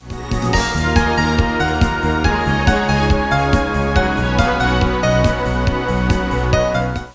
Relativement correct en synthèse FM.